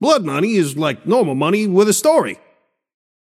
Shopkeeper voice line - Blood money is like normal money with a story.